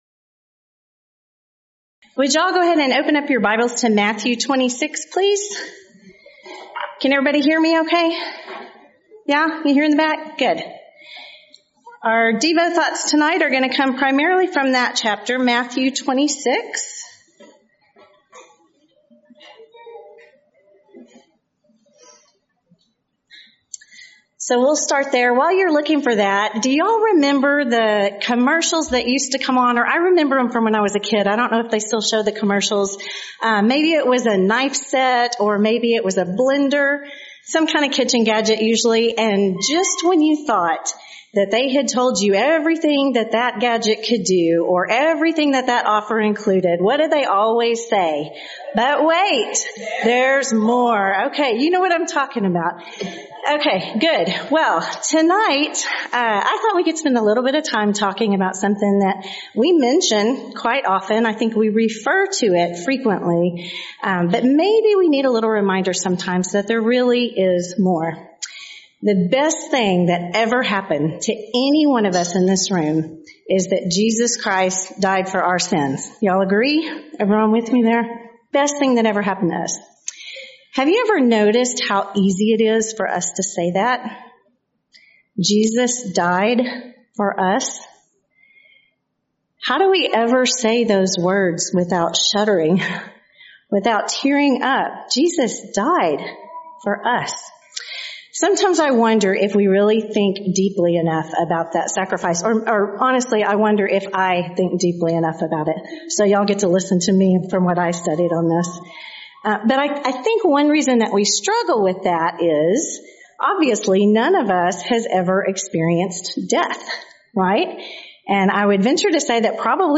Event: 5th Annual Texas Ladies In Christ Retreat Theme/Title: Studies in James